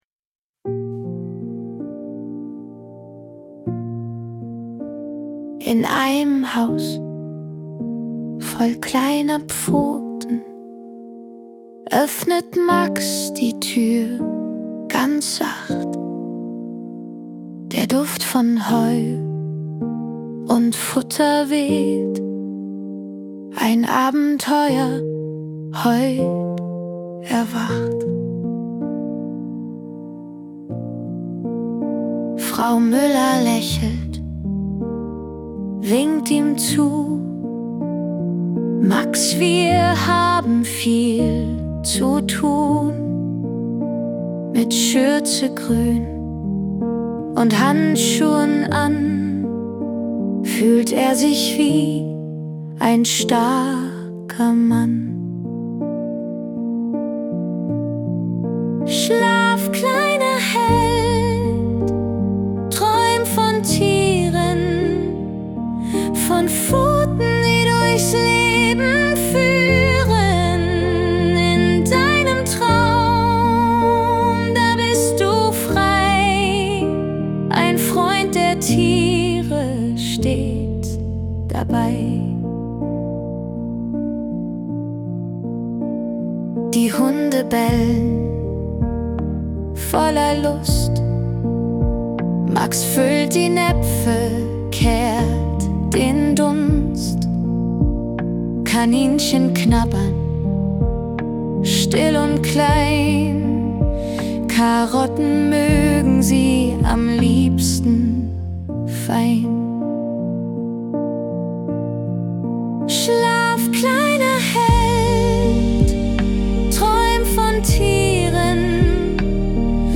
Das Schlaflied zur Geschichte
🎵 Musik und Gesang: Suno | AI Music